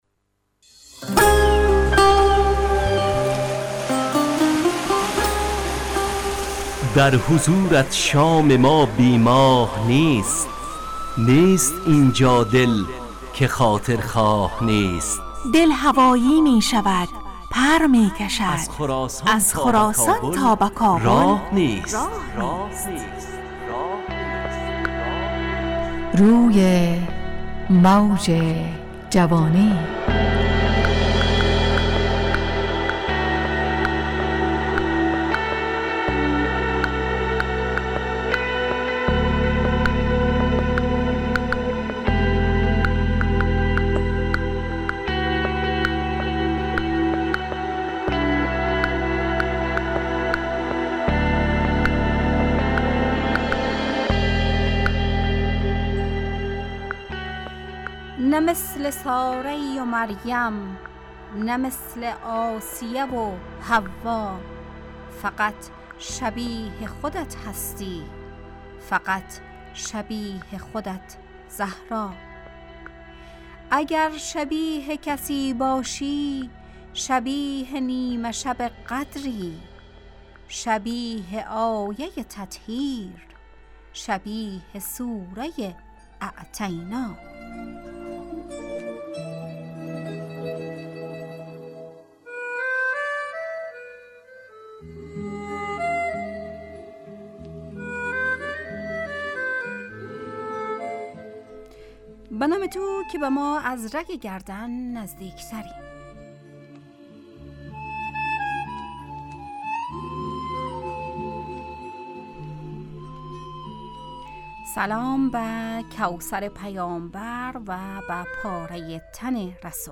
همراه با ترانه و موسیقی مدت برنامه 55 دقیقه . بحث محوری این هفته (سواد) تهیه کننده